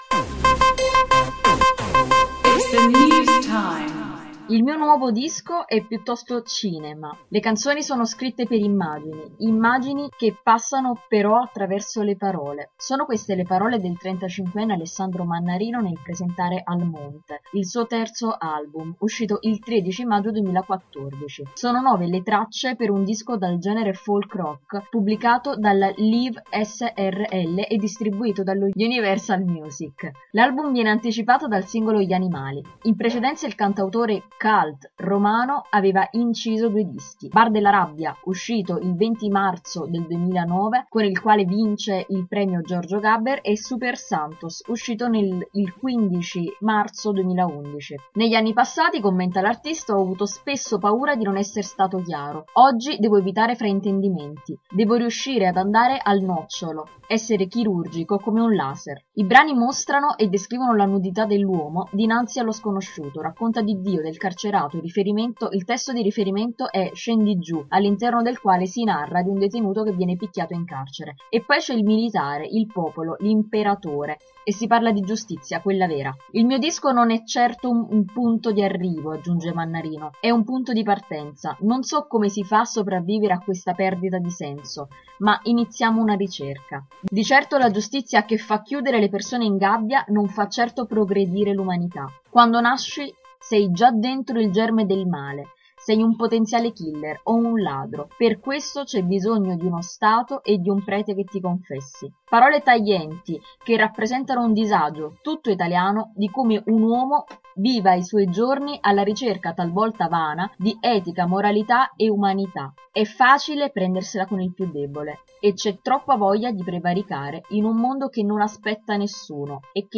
Se vuoi ascoltare l’articolo letto dalle nostre redattrici clicca qui